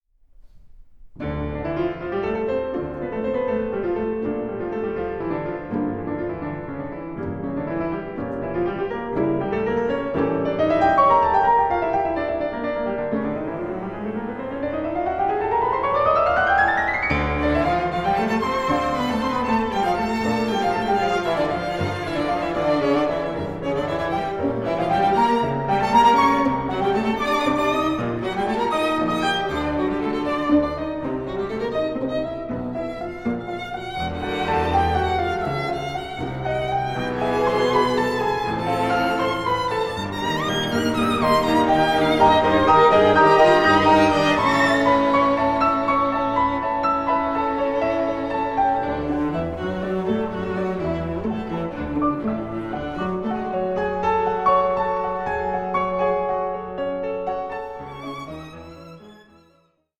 for violin, piano and string quartet